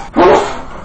sneeze2.wav